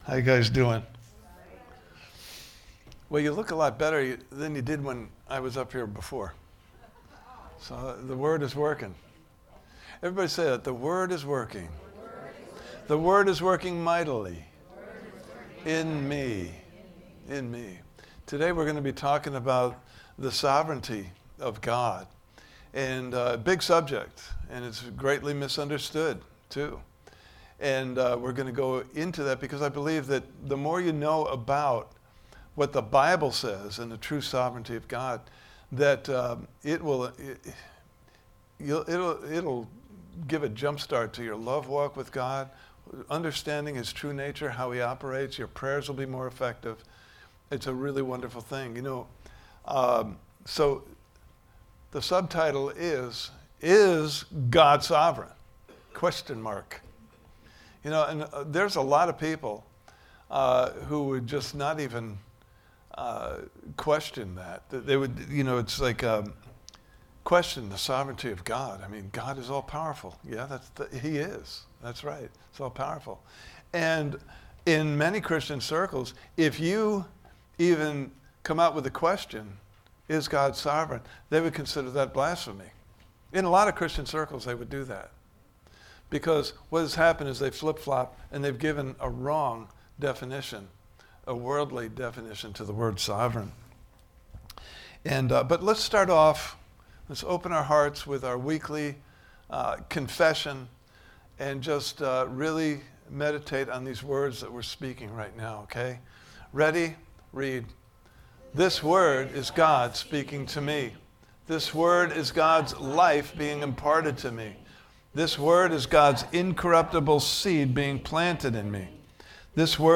Series: Why Do Bad Things Happen To Good People? Service Type: Sunday Morning Service « Part 4: The Armor of God!